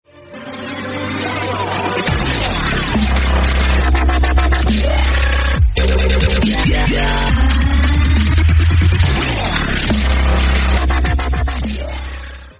Dubstep Gun